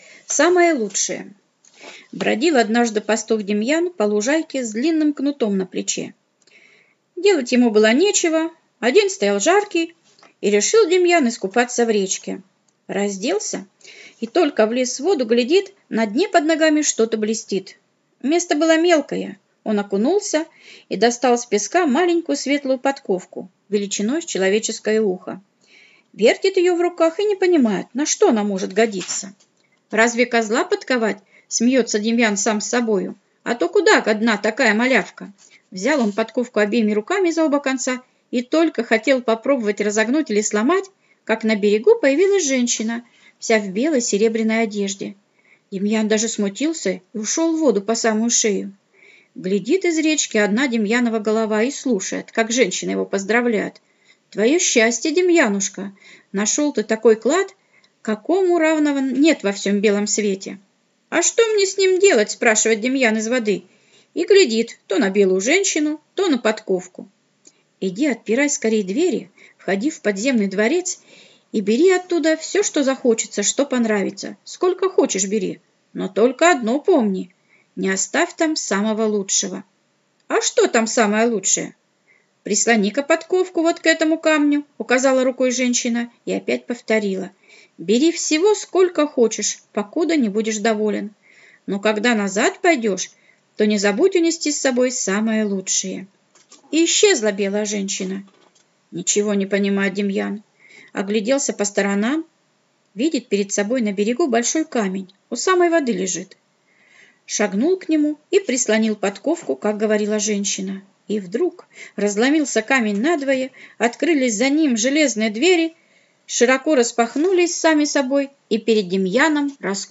Аудиосказка «Самое лучшее»